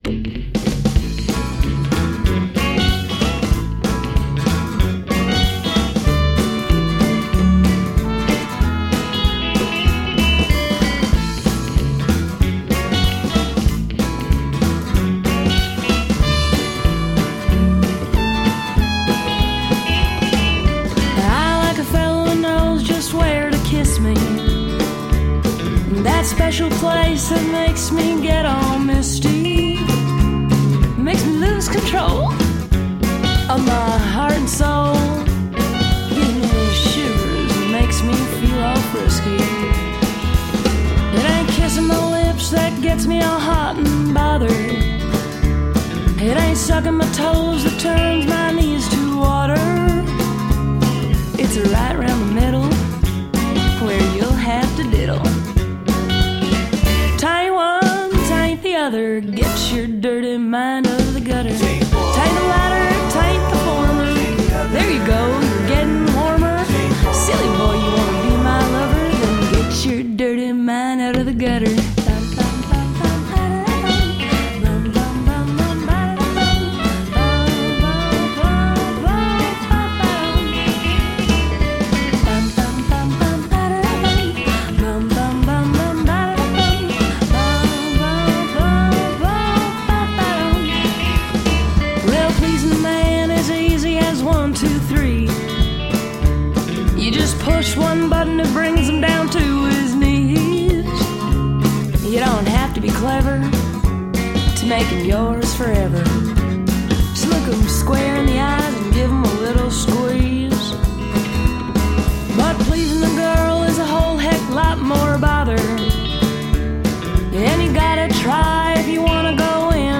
Country, rockabilly, western swing and lounge.
Tagged as: Alt Rock, Folk-Rock, Country